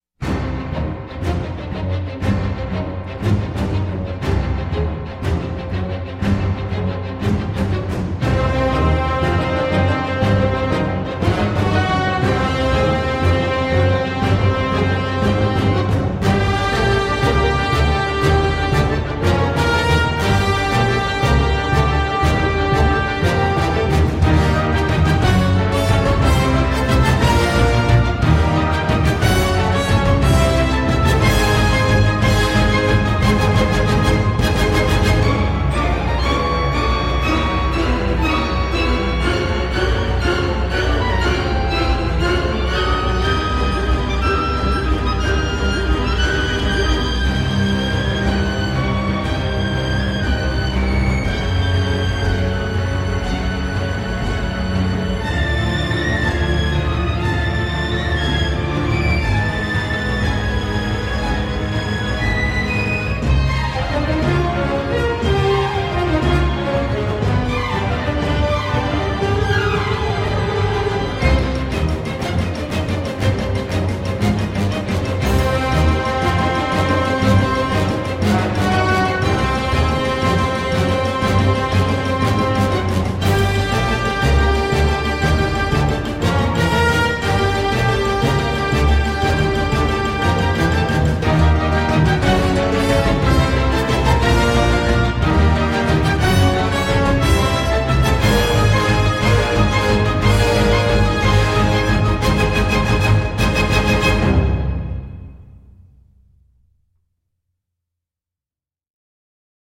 l’electro vitaminée
Amateurs de musique qui pique les oreilles, n’hésitez pas.